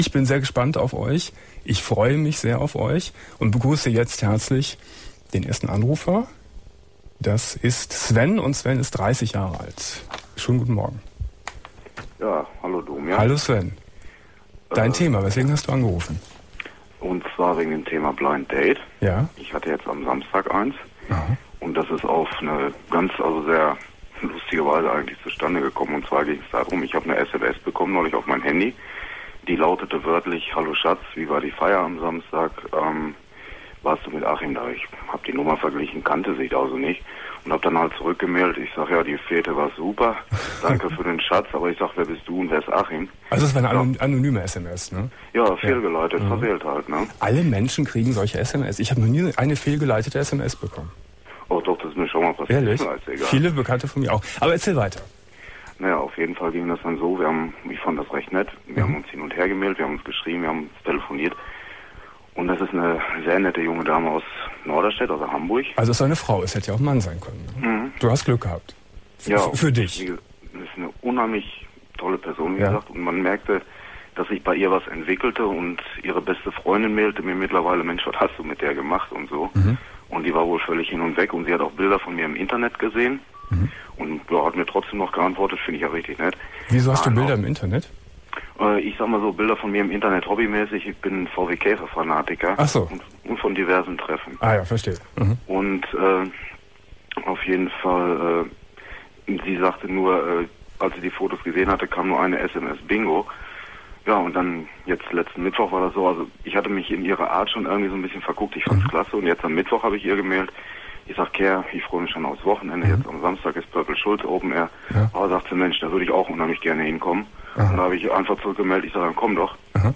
04.09.2001 Domian Thema: Offen ~ Domian Talkradio Archiv Podcast